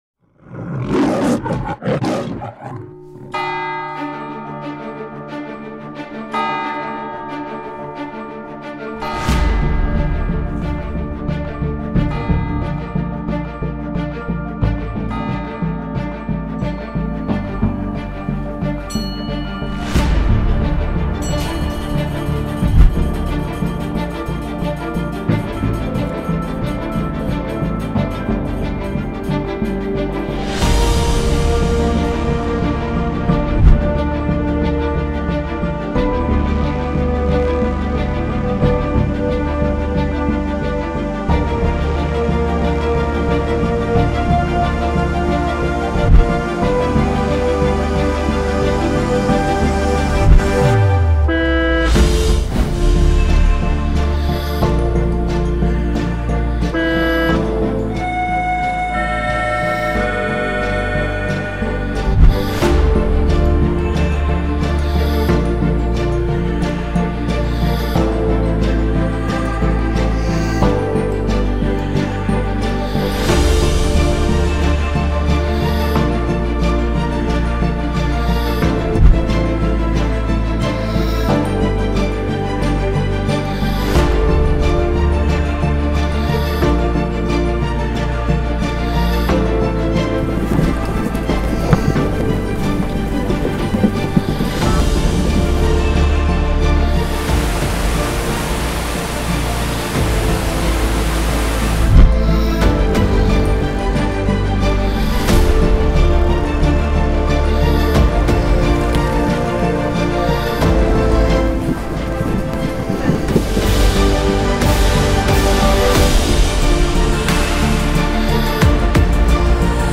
Eine Tonspur wird abgespielt und der Besucher darf erraten, welche Orte der Stadt Luzern vorkommen.